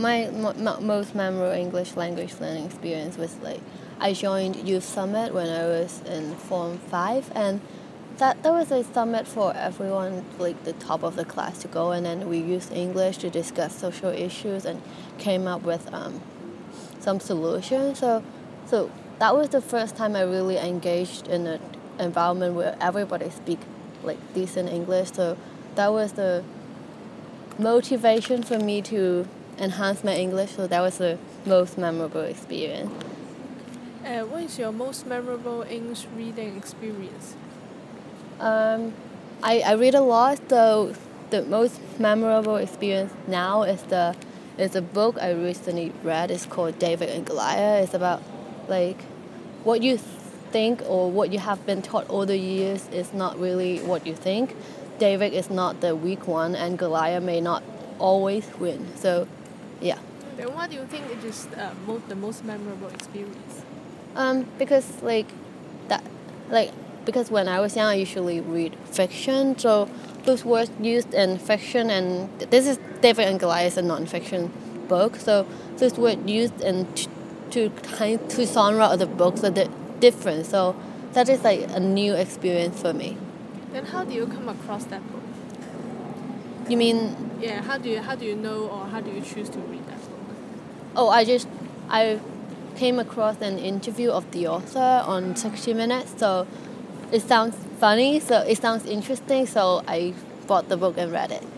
Subcategory: Activity, Non-fiction, Reading, Speech